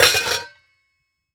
metal_lid_movement_impact_04.wav